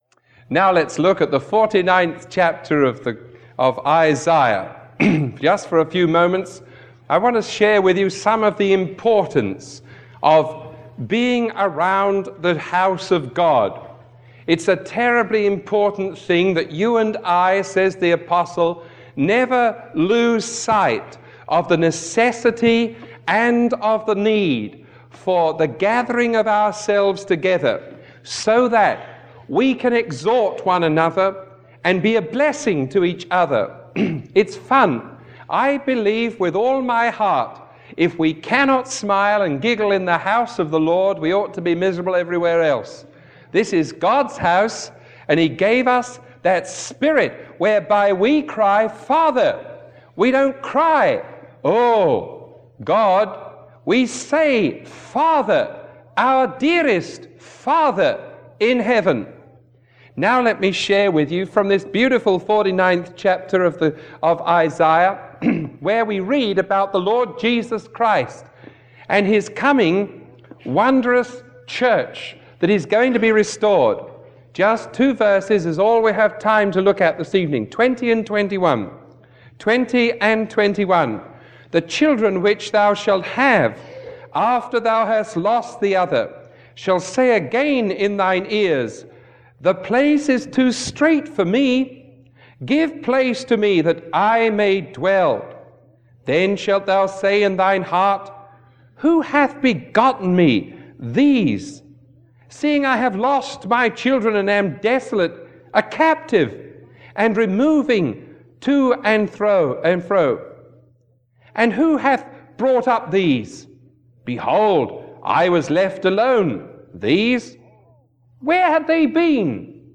Sermon 0173A recorded on May 18